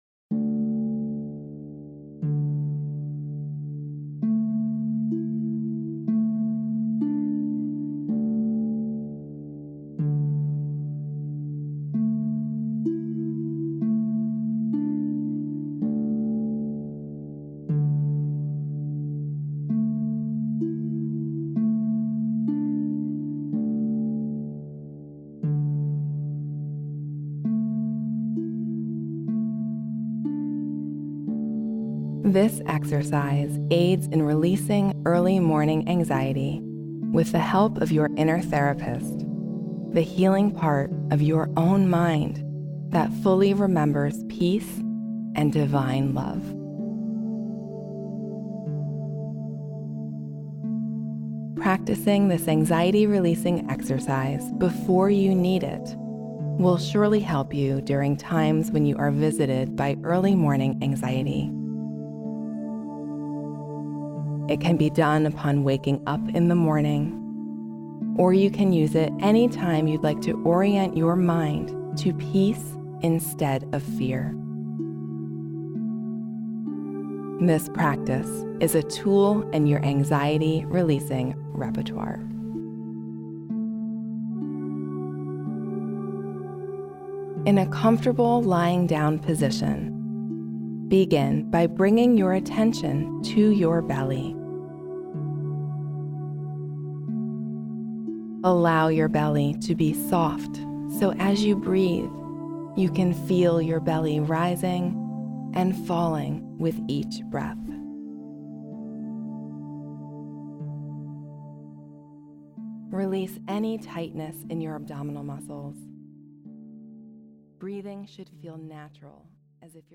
Use this 20-minute meditation any time you need to orient your mind to peace and alleviate anxiety.